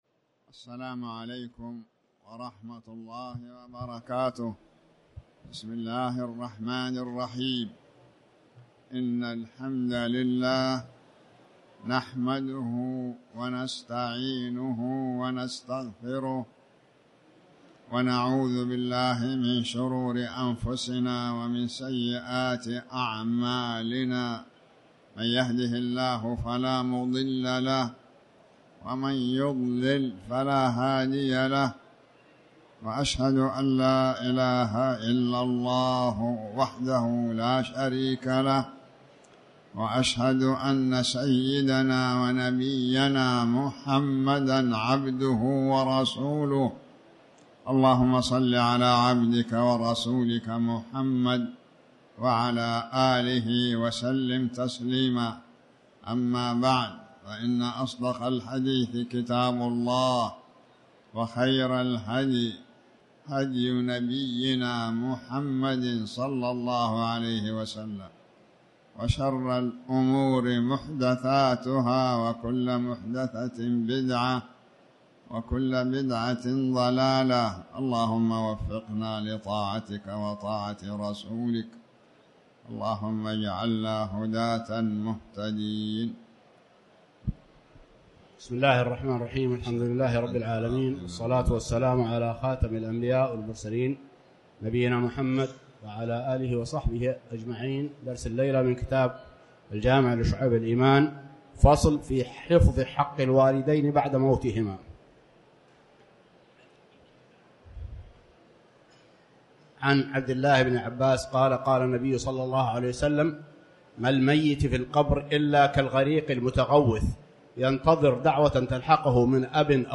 تاريخ النشر ٢٤ ربيع الأول ١٤٤٠ هـ المكان: المسجد الحرام الشيخ